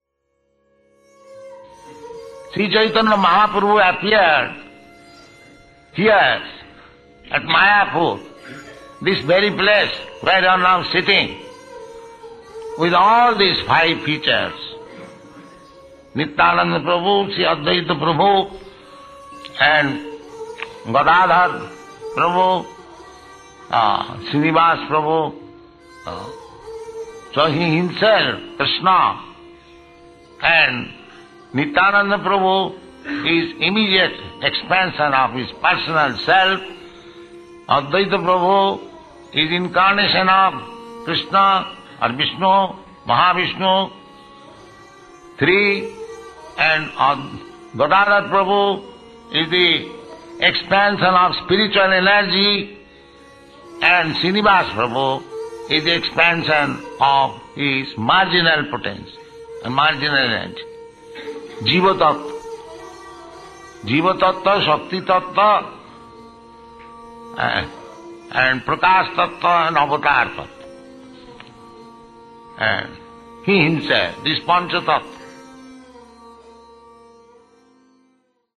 (740303 - Lecture CC Adi 07.03 - Mayapur)